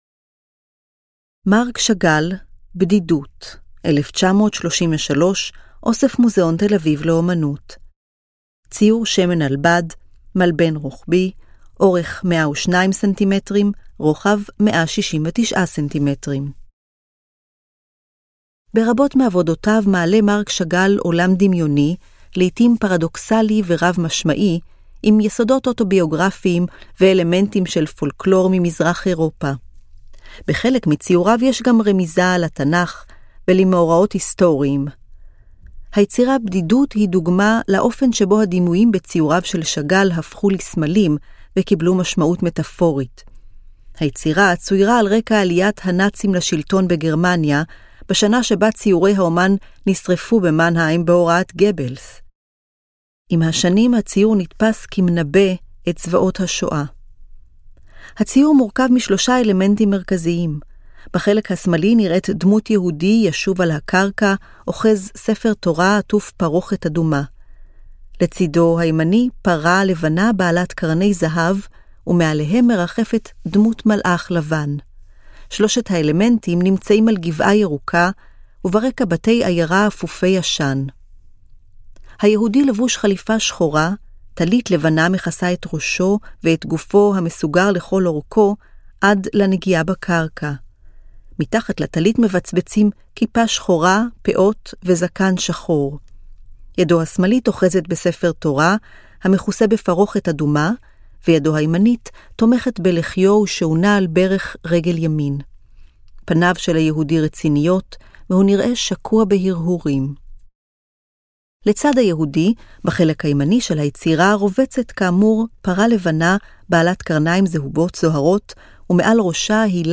היצירות כולן הונגשו כאמור באמצעות: טקסט – תיאור מורחב המתאר את פרטי היצירה, אודיו – הקלטת התיאור המורחב אותו ניתן לשמוע במדריך הקולי של המוזיאון, גרפיקה טקטילית - הבלטה של היצירות באמצעות מדפסות ברייל, ברייל – תרגום הטקסט המורחב לכתב ברייל.